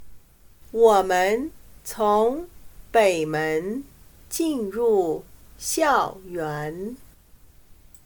我们从北门进入校园。/Wǒmen cóng běi mén jìnrù xiàoyuán./Entramos al campus por la puerta norte.